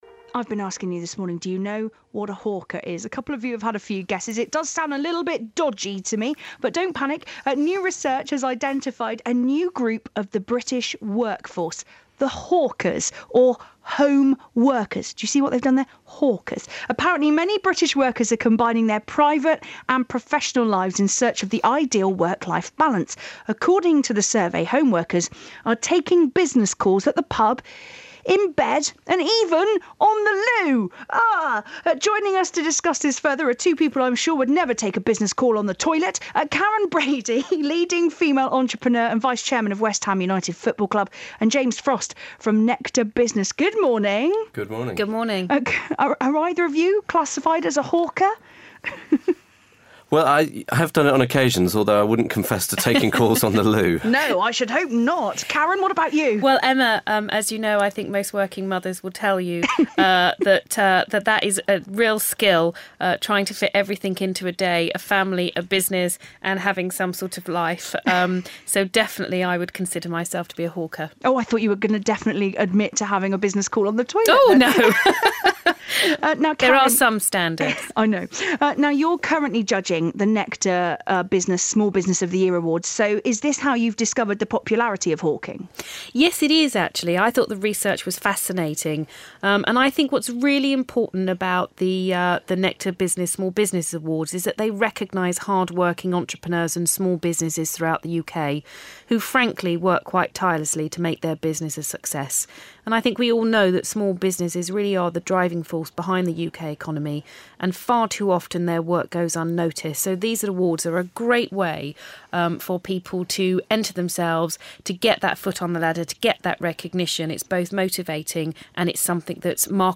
Karren Brady talks working from home on BBC Somerset.